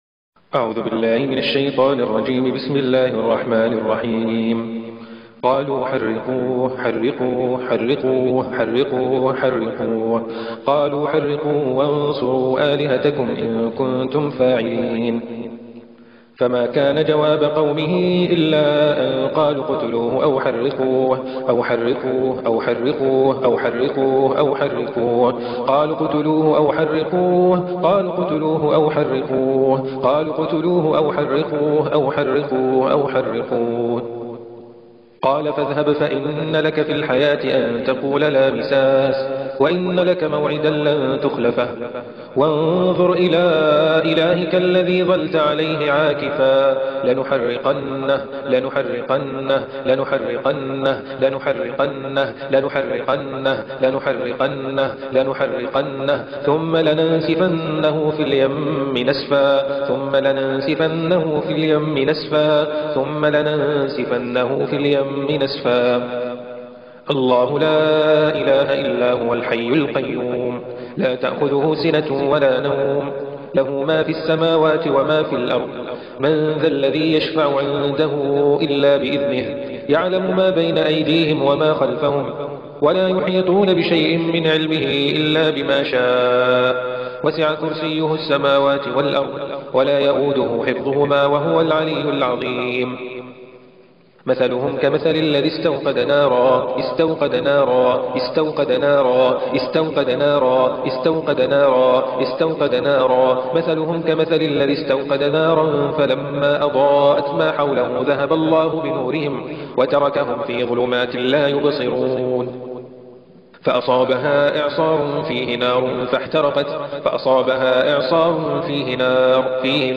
জিনকে জ্বালিয়ে দেওয়ার রুকইয়াহ
অবাধ্য-জিনকে-জ্বালিয়ে-দেওয়ার-রুকইয়াহ.mp3